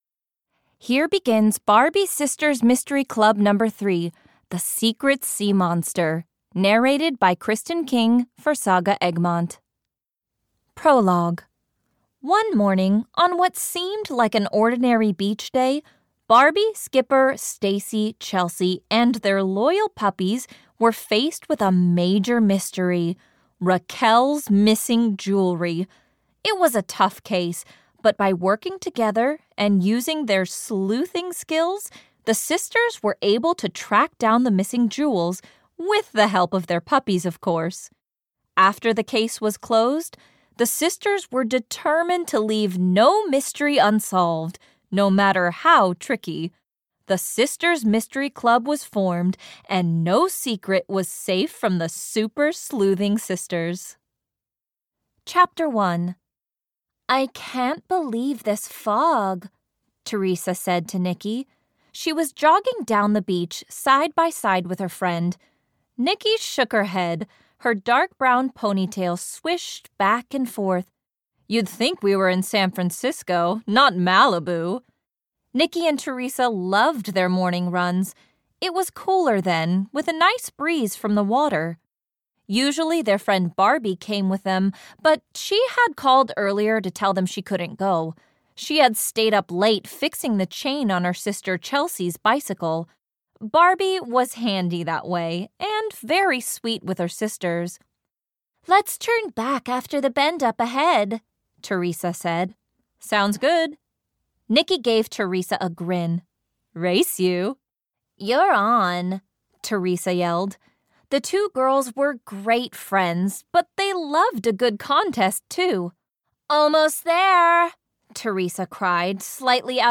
Audio knihaBarbie - Sisters Mystery Club 3 - The Secret Sea Monster (EN)
Ukázka z knihy